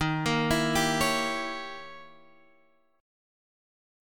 D#7b9 Chord